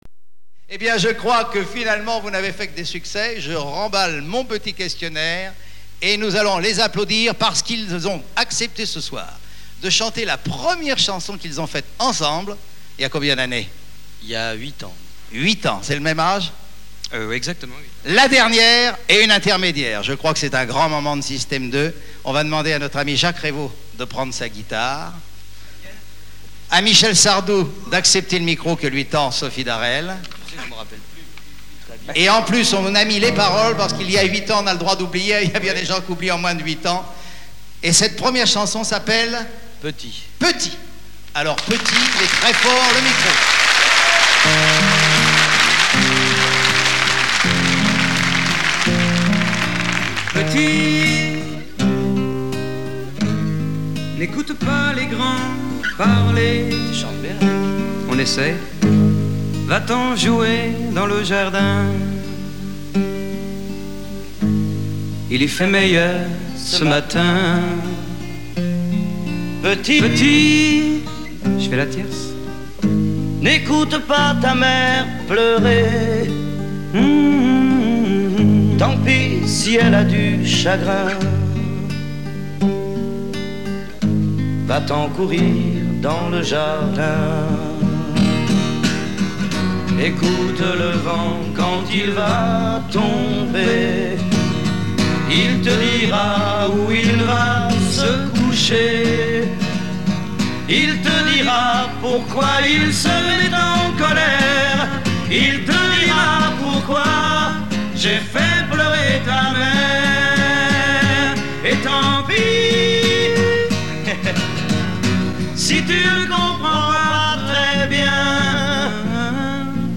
Versions TV